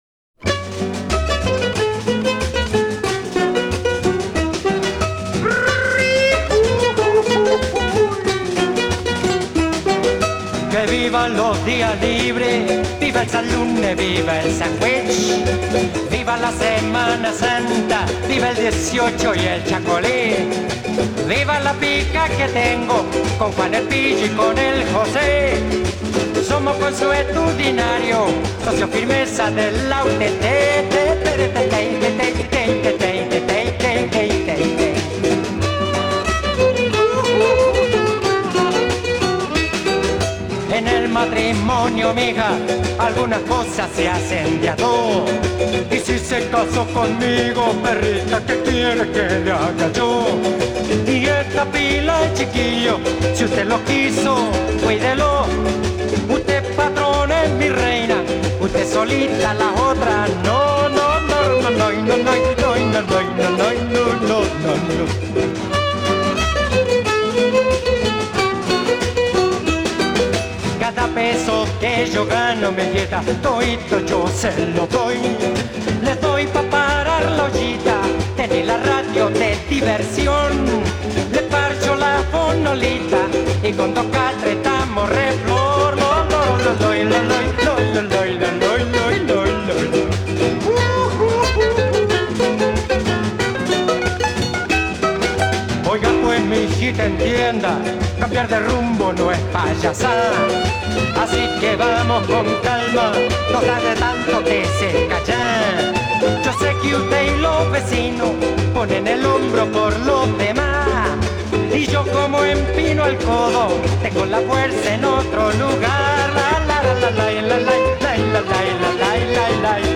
ESTILO: Cantautor